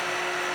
grind.wav